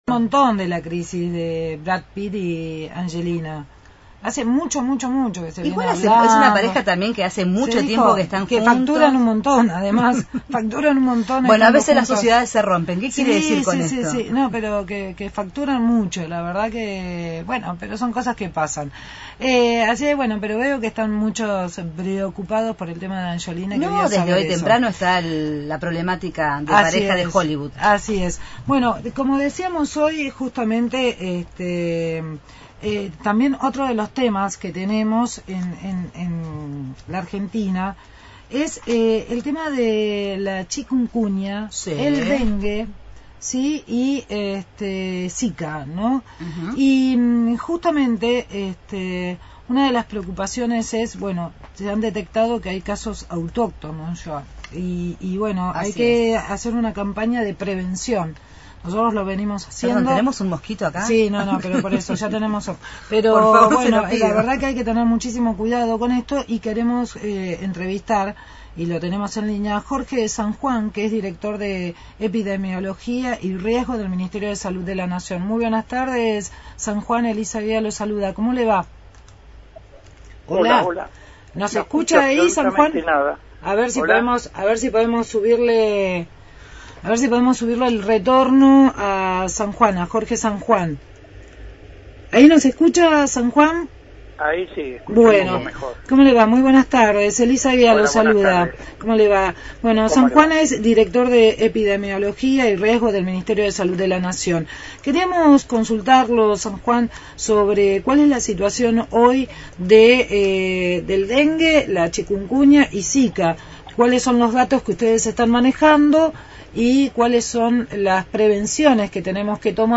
Entrevista en Darás que hablar a Jorge San Juan, director nacional de Epidemiología dijo que hay una «epidemia» de dengue.